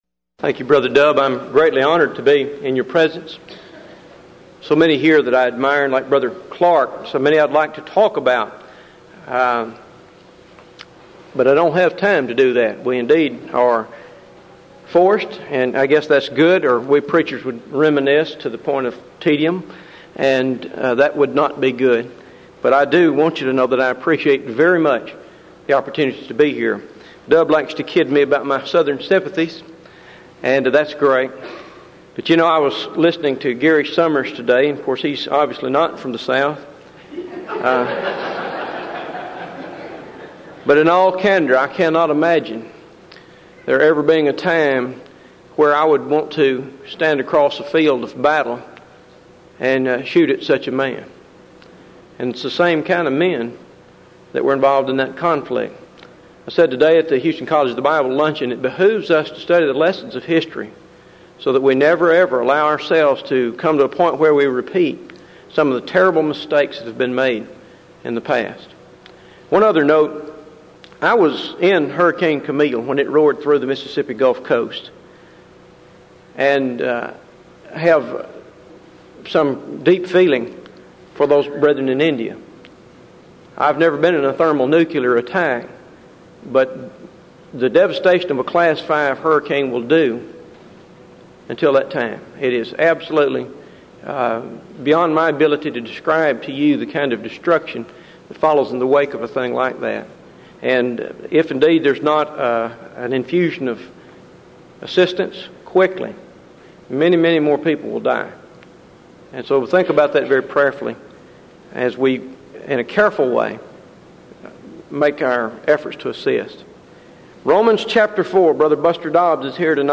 Event: 1996 Denton Lectures
lecture